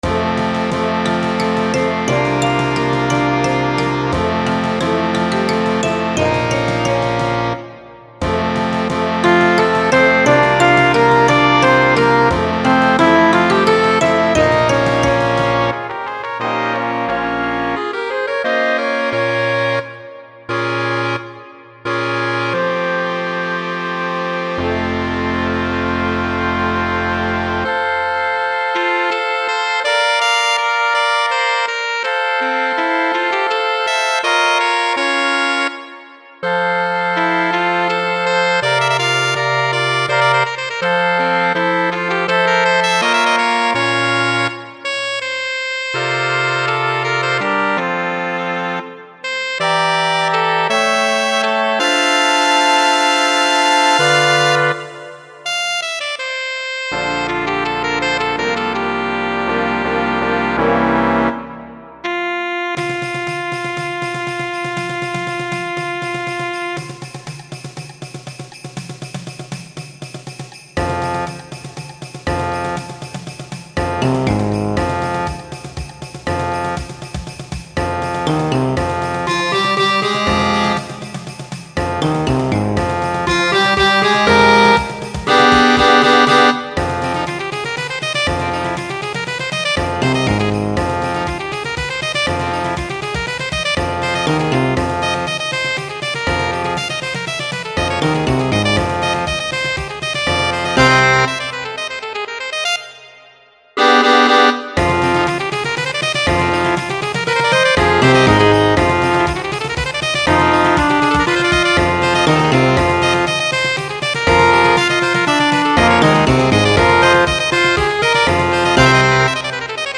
Voicing: Alto Saxophone w/ Band